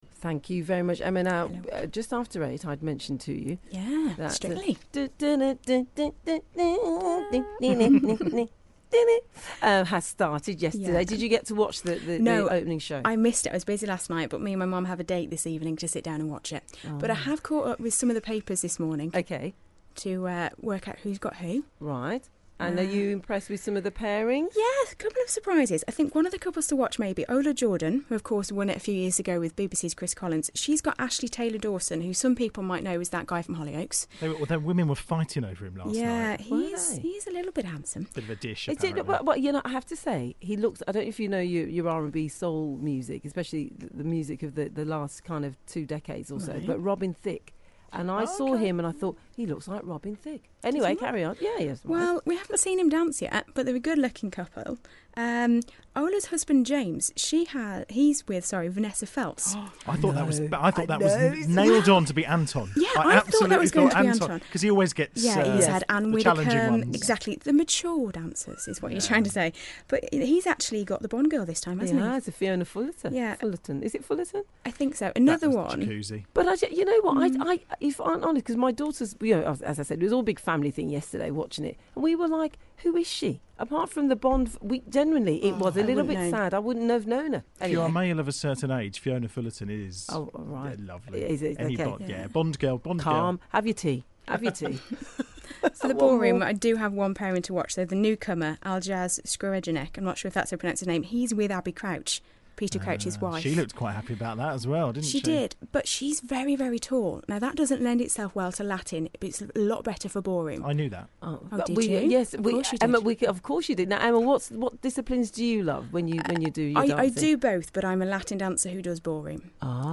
(Broadcast on BBC WM September 2013).